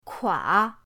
kua3.mp3